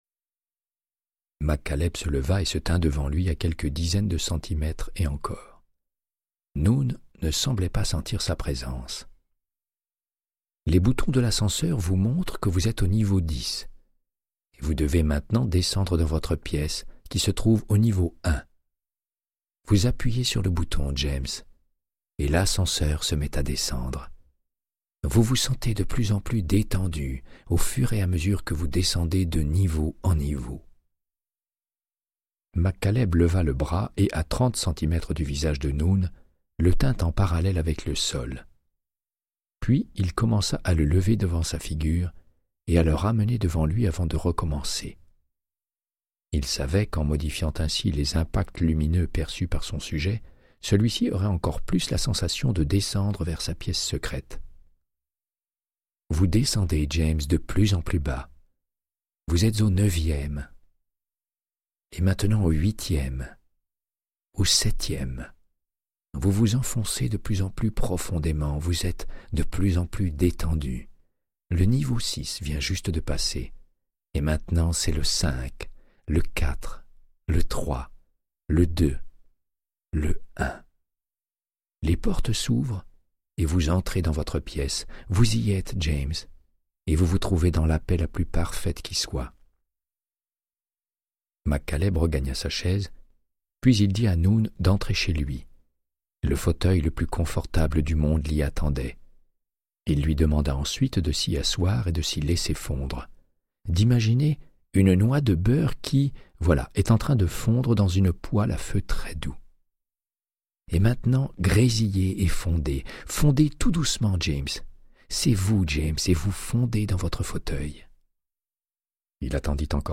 Audiobook = Créance de sang, de Michael Connellly - 68